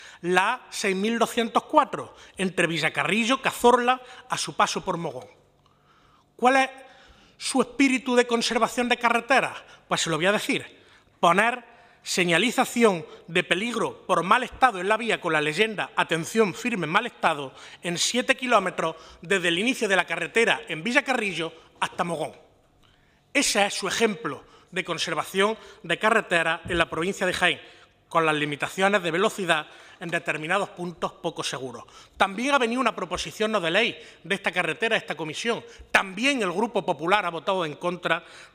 Comisión de Fomento
Cortes de sonido